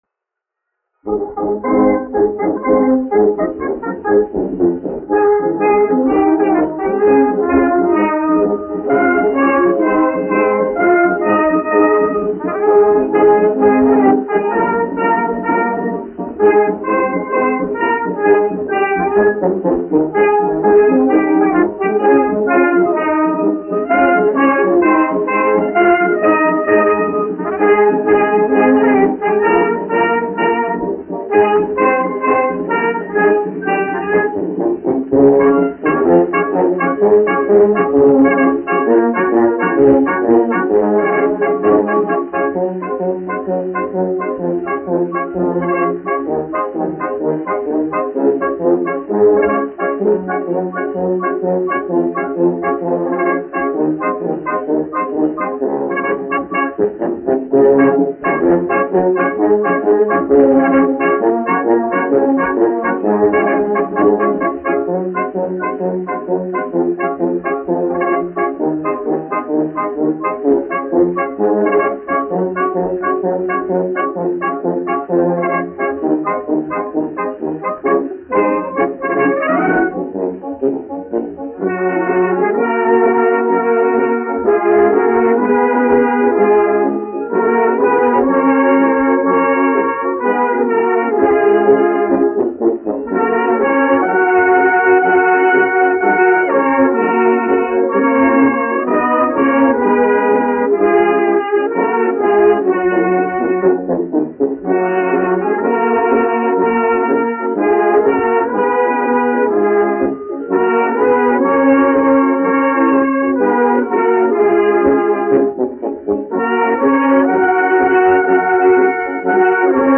1 skpl. : analogs, 78 apgr/min, mono ; 25 cm
Marši
Pūtēju orķestra mūzika
Latvijas vēsturiskie šellaka skaņuplašu ieraksti (Kolekcija)